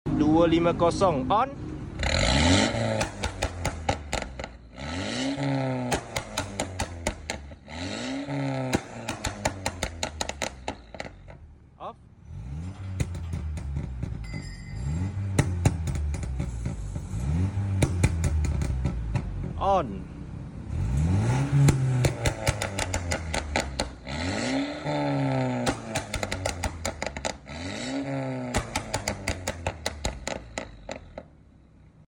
[ON OFF] Mercedes C117 CLA250 Sound Effects Free Download
[ON OFF] Mercedes C117 CLA250 Custom Catback Valvetronic + Downpipe Stage 2!😎🔛🔝 .